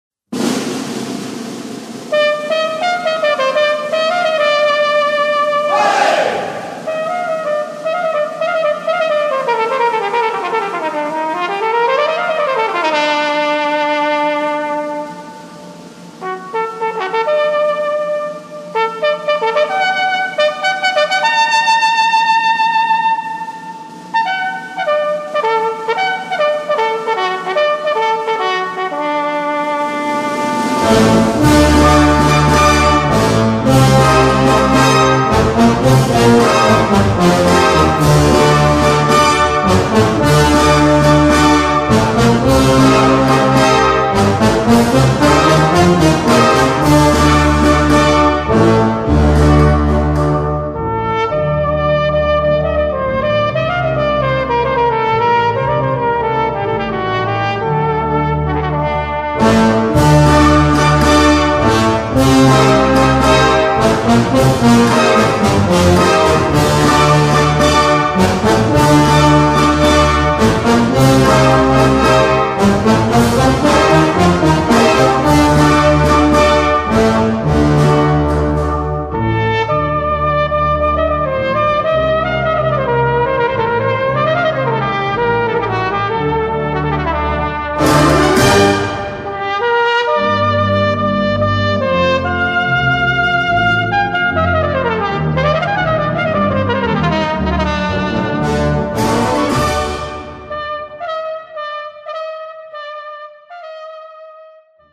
Voicing: Cornet and Brass Band